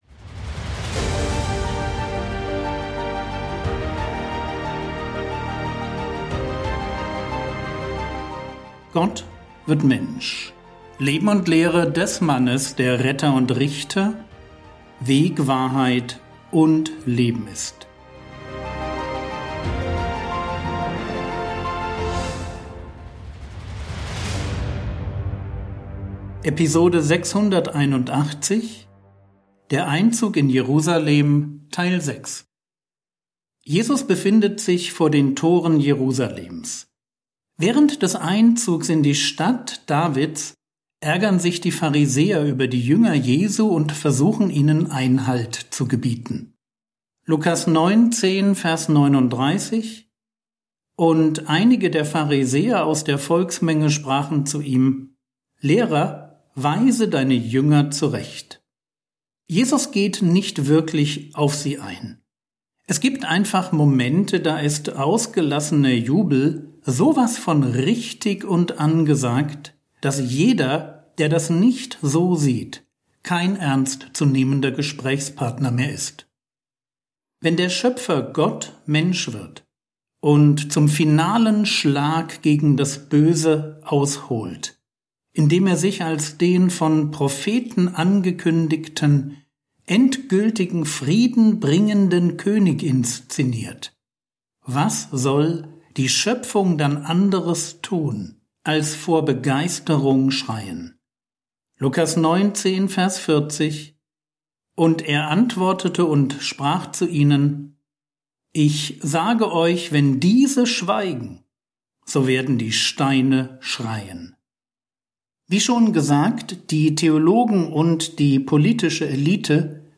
Episode 681 | Jesu Leben und Lehre ~ Frogwords Mini-Predigt Podcast